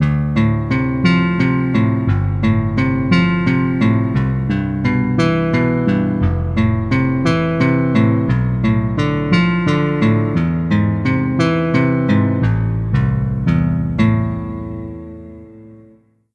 Solo de guitarra
cordófono
arpegio
grave
guitarra